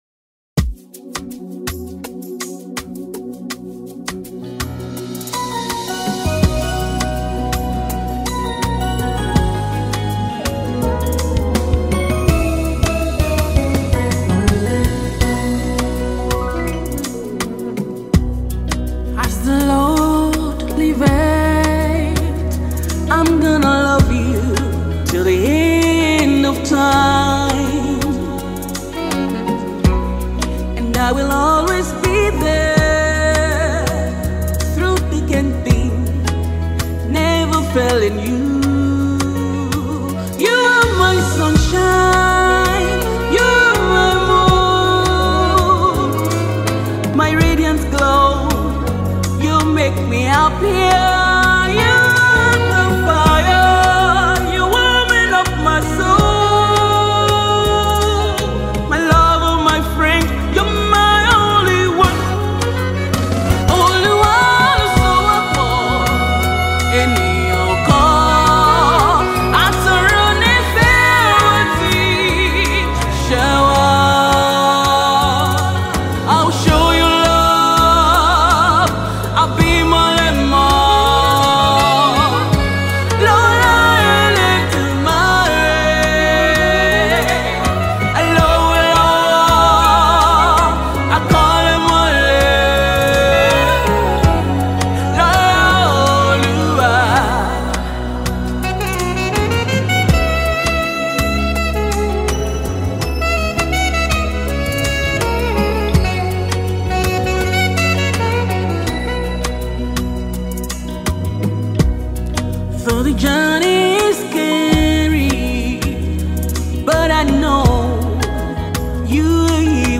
Nigerian talented gospel singer and songwriter